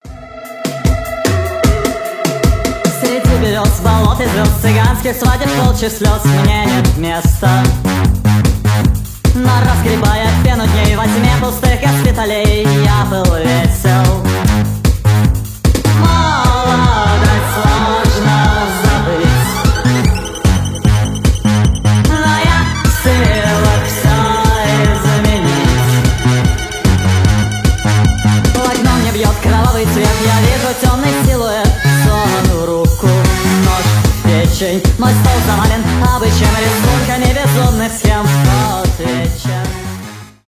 indietronica
indie pop